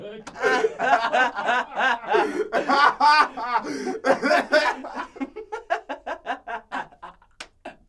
haha2.aiff
sound effect free sound royalty free Memes